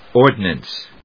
音節ord・nance 発音記号・読み方
/ˈɔɚdnəns(米国英語), ˈɔːdnəns(英国英語)/